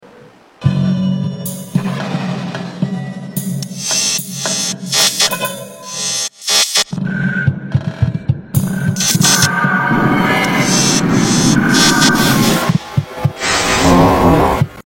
A real-time audiovisual composition exploring swarm structures and generative geometry.